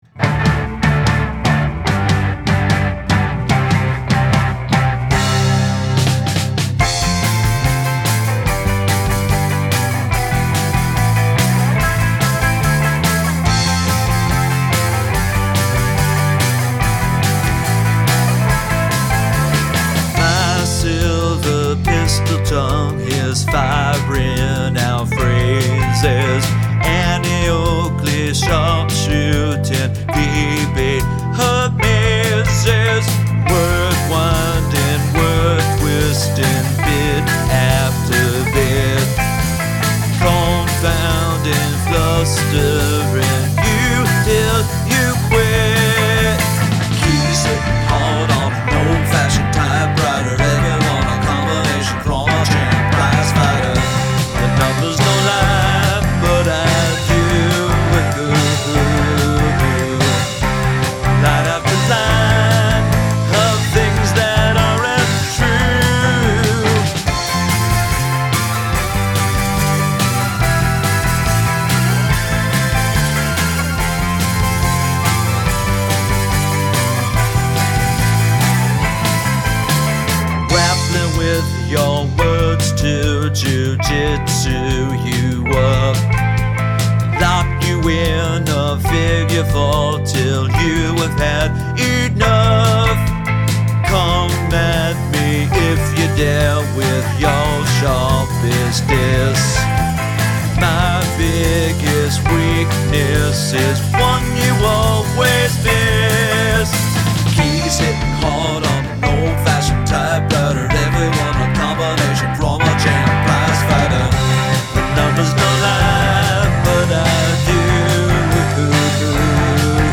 Royal Road is the verse progression of F G Em Am
I like the guitars. Nice bass too.
The pre-chorus is pretty catchy.